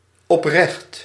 Ääntäminen
IPA: /ɔp.ɾǝχt/